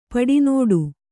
♪ paḍi nōḍu